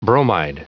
Prononciation du mot bromide en anglais (fichier audio)
Prononciation du mot : bromide